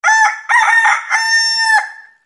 alert sound sound effects